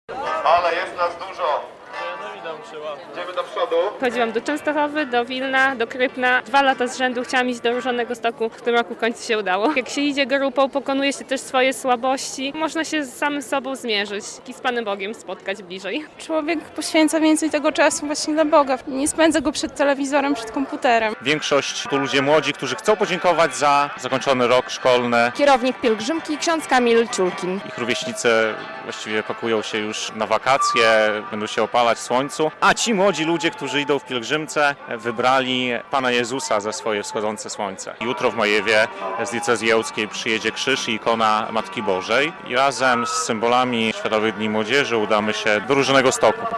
Pielgrzymka do Różanegostoku - relacja